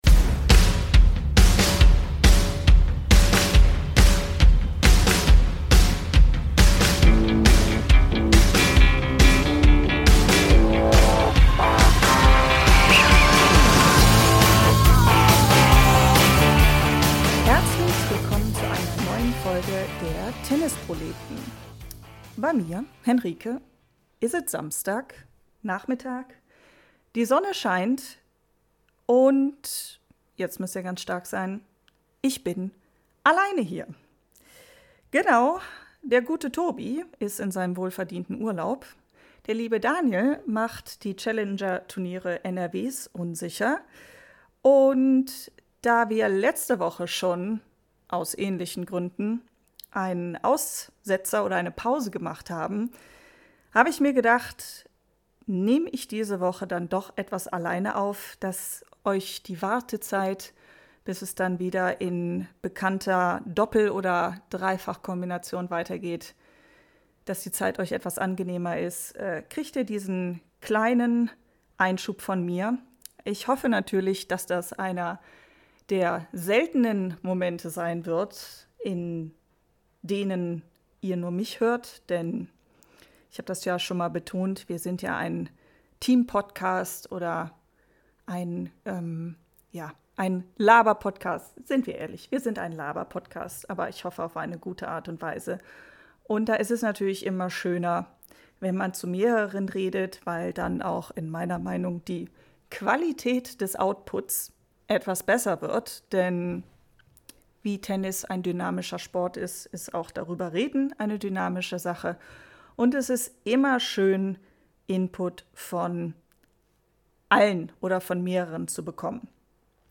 Sie schneidet zum ersten Mal eine Folge und es wollte noch nicht alles mitmachen wie geplant.